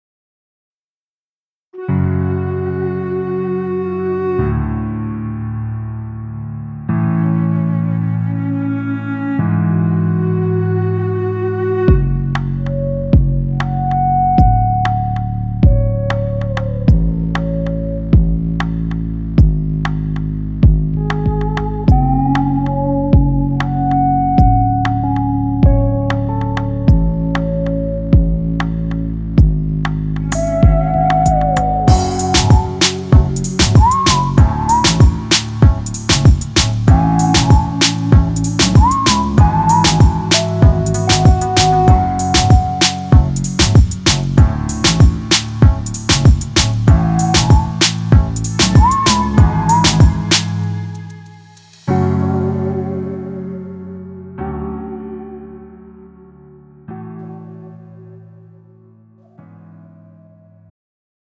음정 원키 장르 가요